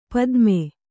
♪ padme